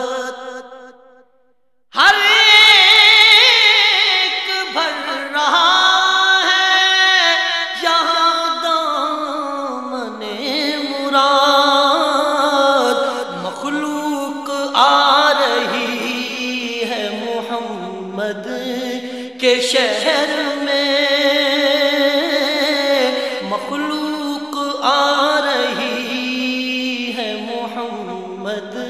Islamic Ringtones